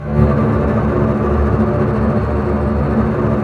Index of /90_sSampleCDs/Roland LCDP08 Symphony Orchestra/STR_Cbs Bow FX/STR_Cbs Tremolo